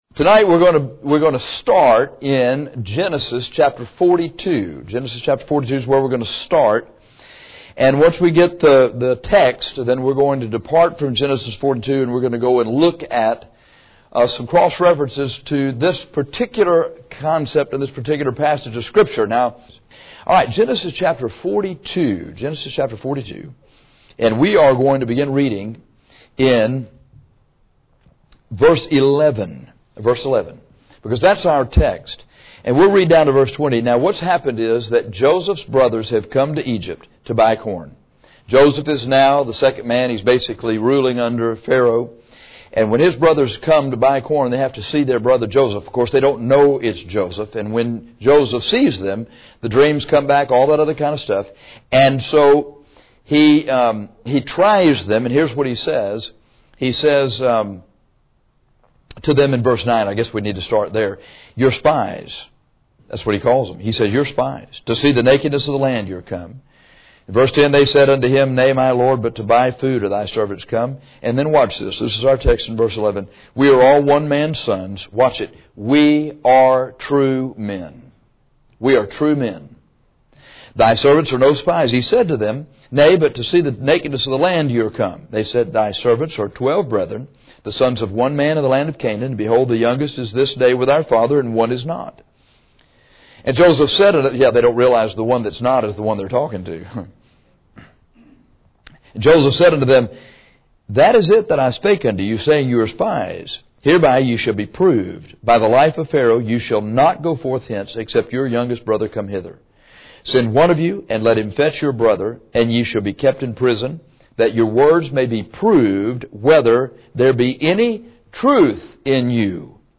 We are preaching this text tonight, because we are to be true men.